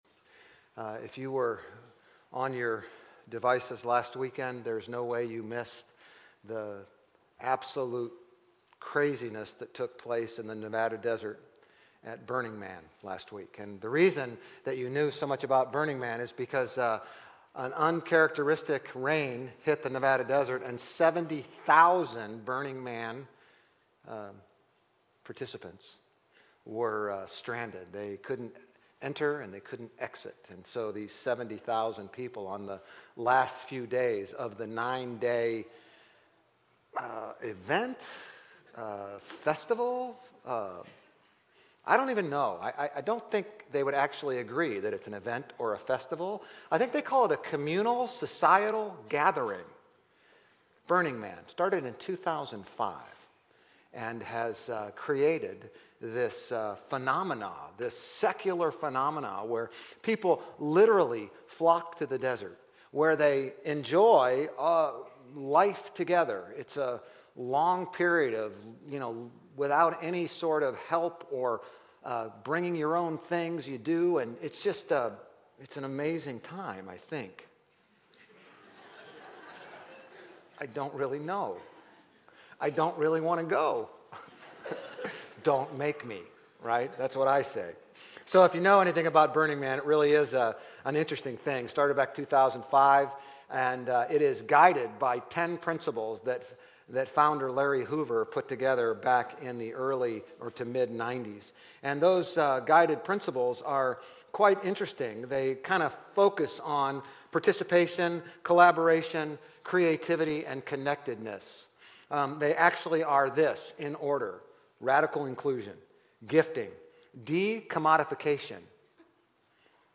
GCC-OJ-September-10-Sermon.mp3